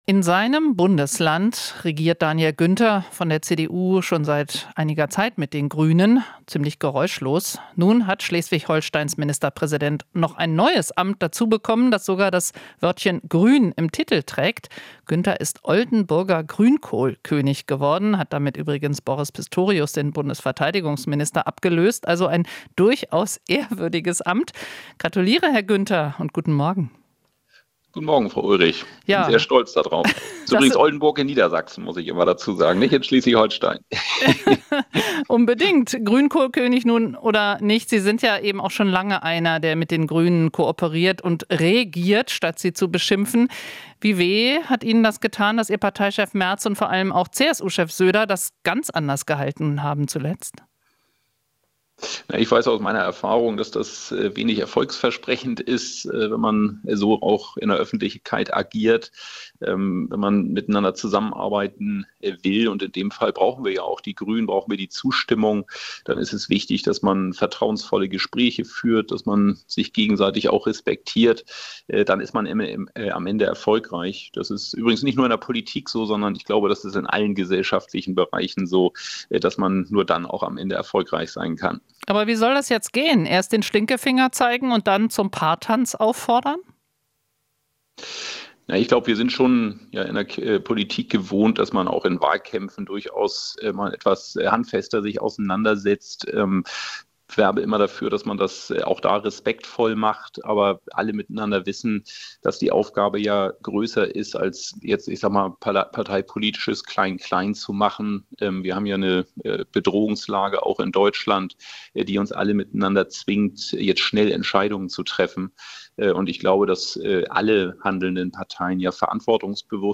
Interview - Günther (CDU) zuversichtlich in Verhandlungen mit Grünen | rbb24 Inforadio